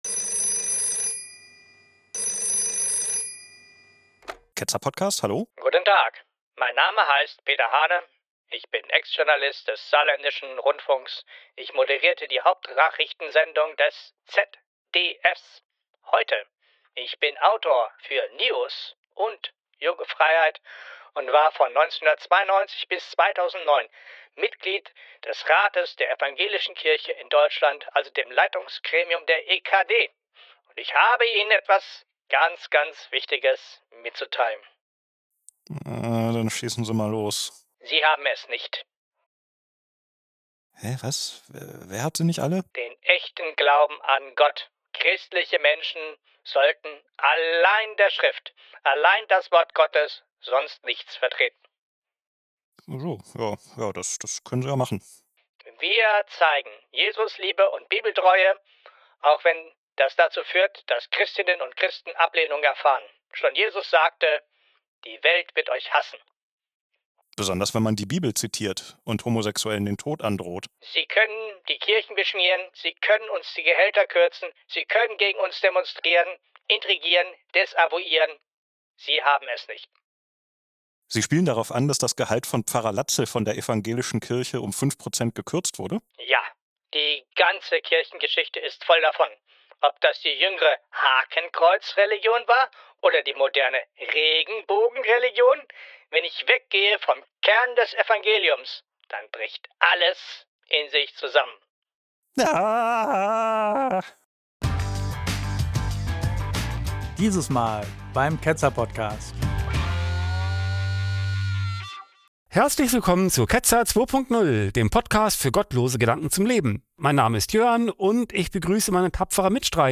Peter Hahne, bekannt als Nachrichtensprecher des ZDF, ruft bei uns an! Er erklärt uns, warum allein die Bibel unser Fundament sein kann, und sonst nichts!